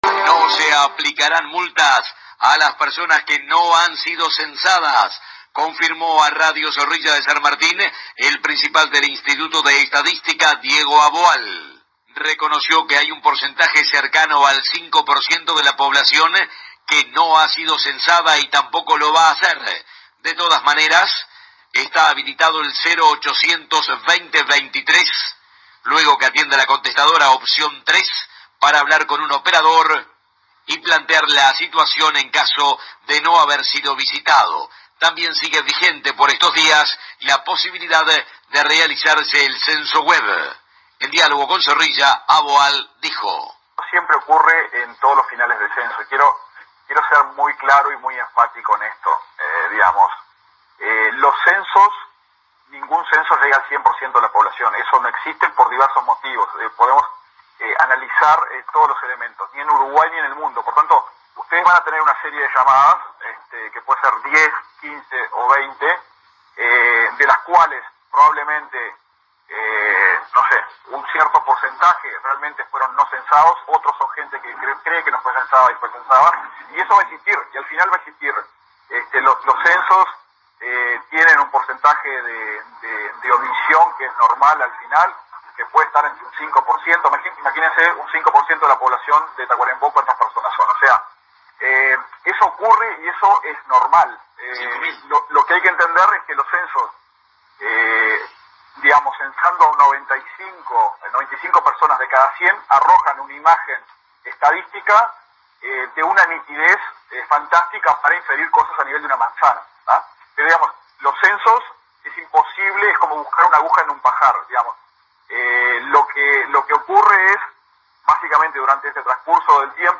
El Director del Instituto Nacional de Estadísticas (INE) dijo a Radio Zorilla de San Martín, de Tacuarembó, que no se van a aplicar multas a las personas que no hayan realizado el Censo 2023, en ninguna de sus modalidades.